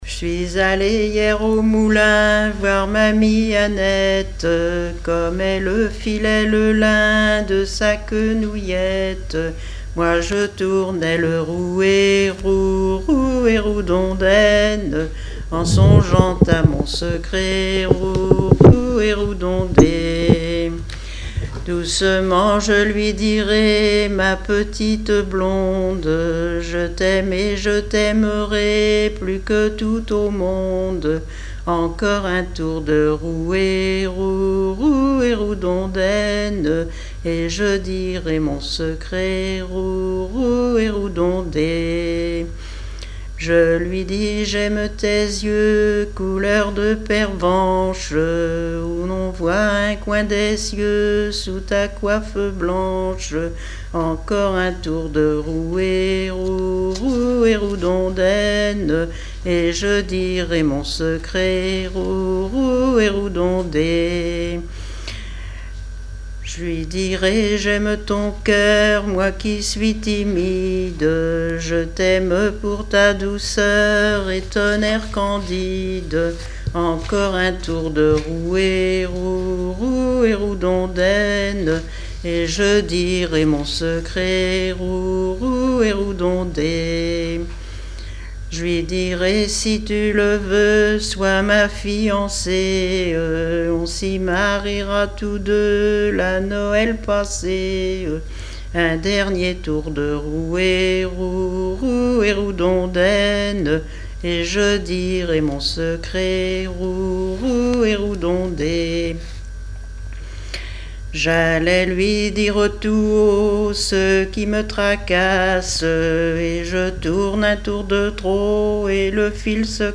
strophique
répertoire de chansons populaires
Pièce musicale inédite